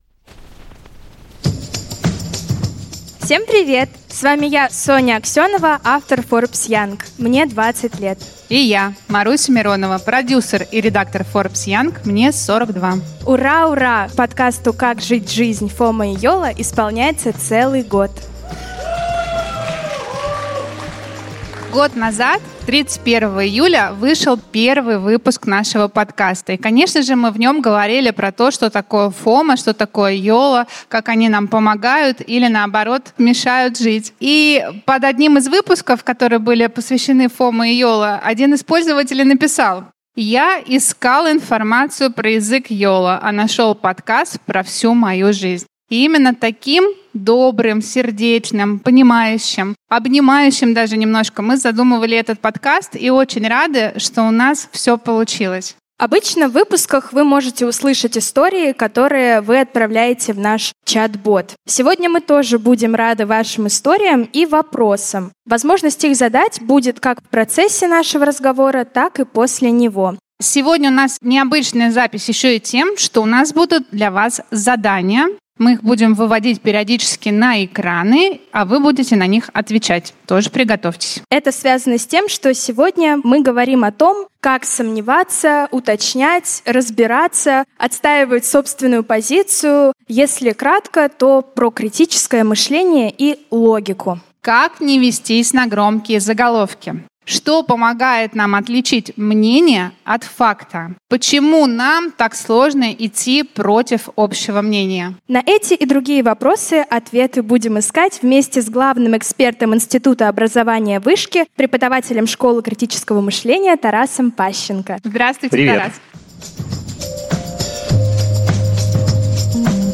В выпуске мы также слушаем истории и вопросы аудитории и решаем логические задачи.
Этот выпуск мы записывали в пространстве «Сфера X5» в парке Горького, которое стало местом силы творческого и профессионального комьюнити.